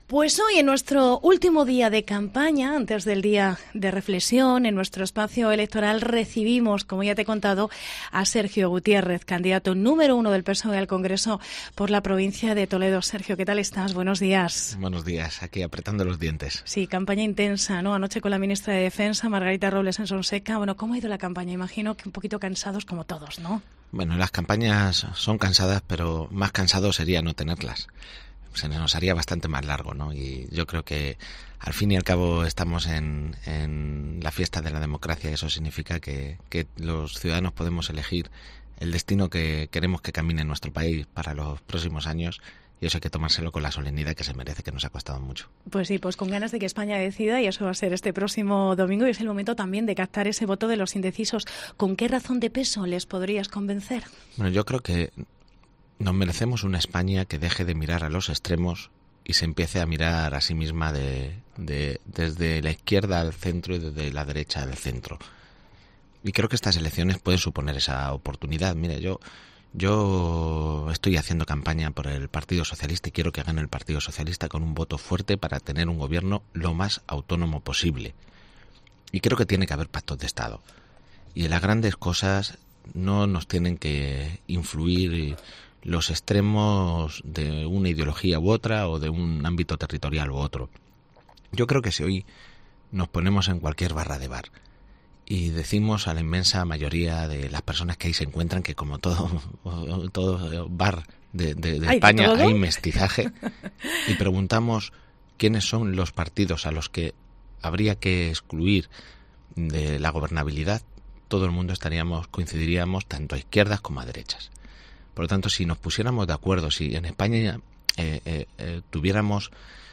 AUDIO: Sergio Gutiérrez, cabeza de lista del PSOE por Toledo al Congreso, ha visitado la redacción de COPE Toledo y ha analizado la actualidad...
ENTREVISTA